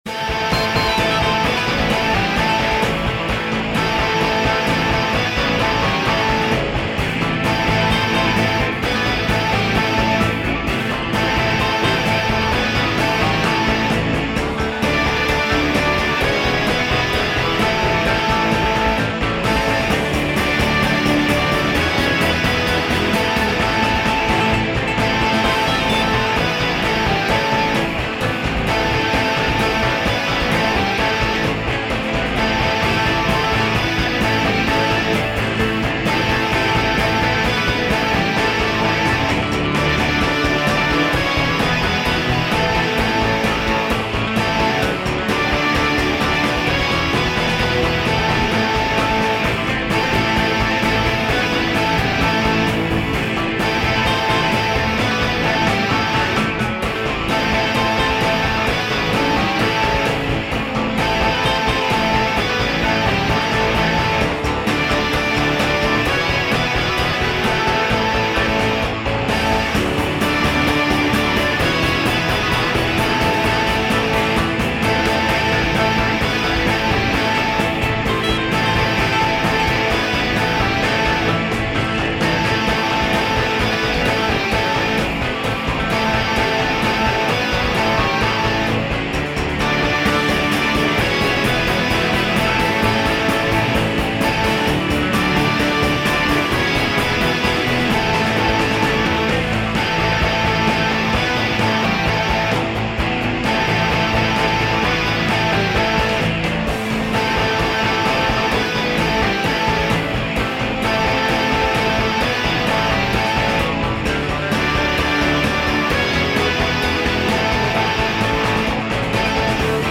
midi-demo 2 midi-demo 3